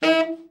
TENOR SN  21.wav